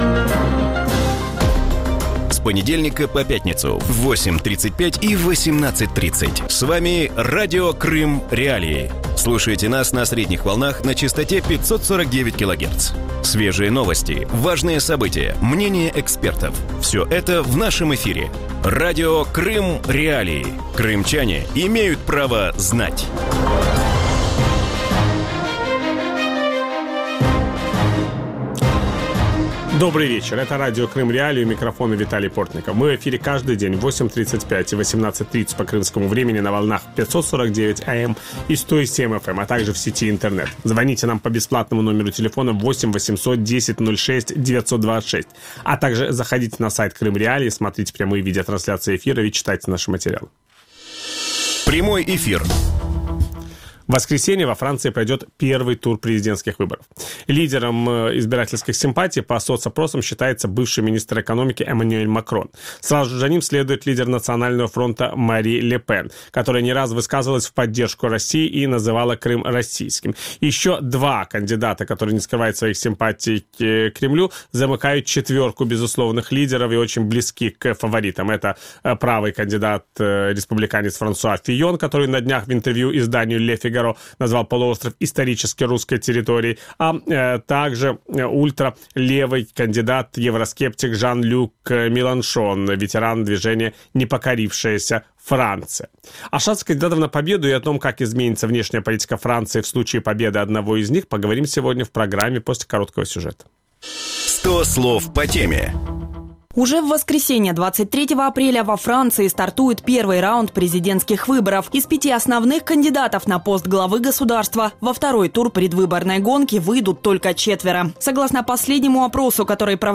У вечірньому ефірі Радіо Крим.Реалії обговорюють майбутні президентські вибори у Франції.
Ведучий – Віталій Портников.